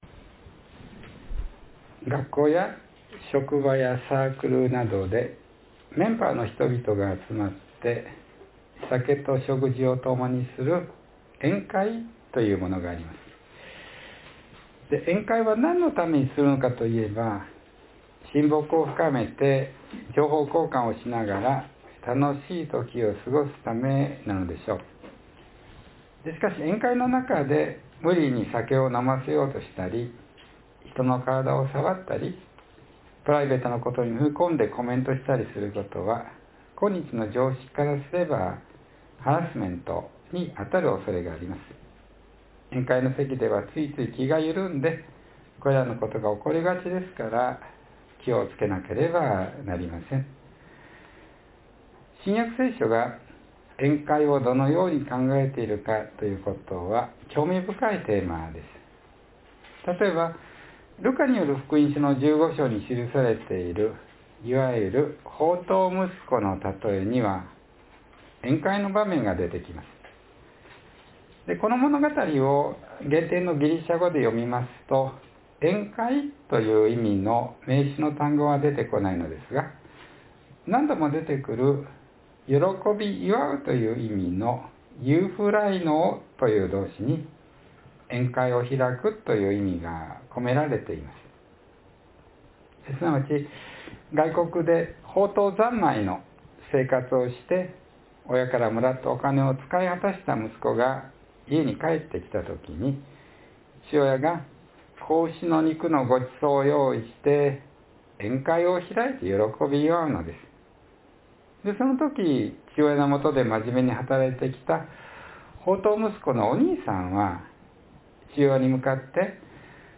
（3月1日の説教より）